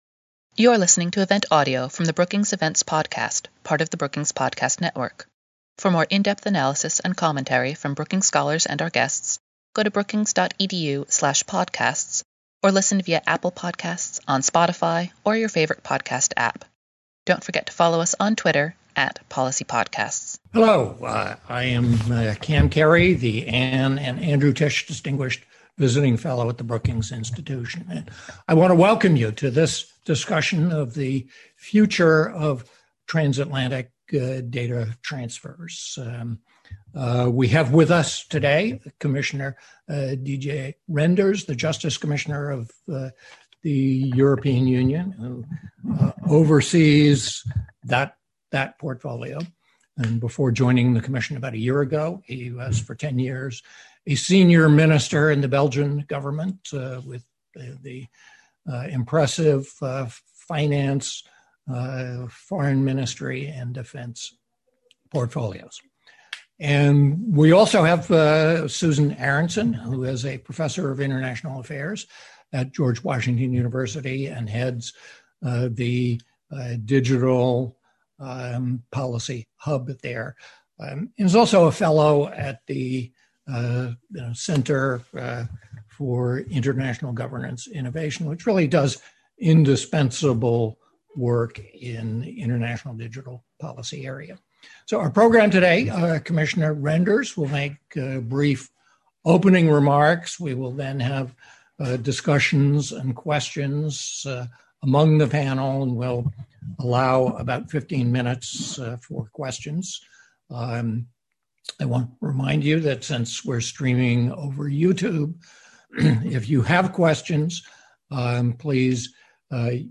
E U Justice Commissioner Didier Reynders made brief opening remarks, followe d by a panel discussion and questions.